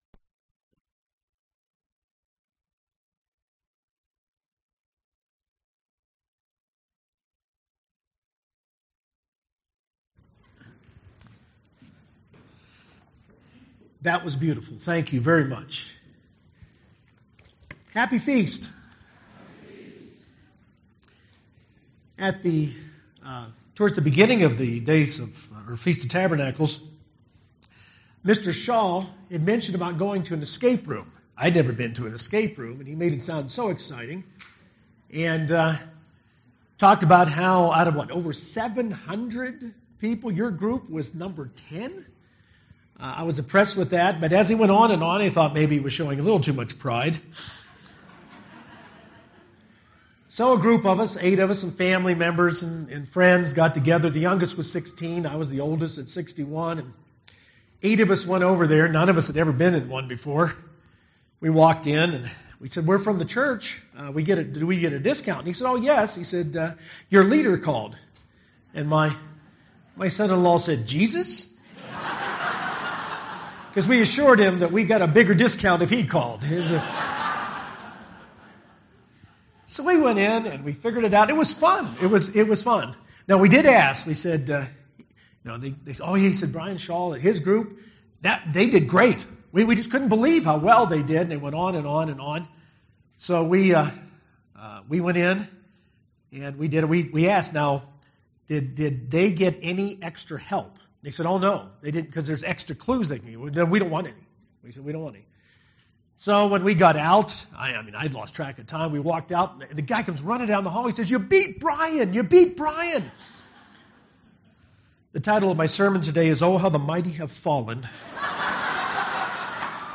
This sermon was given at the Steamboat Springs, Colorado 2017 Feast site.